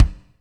KIK M R K03R.wav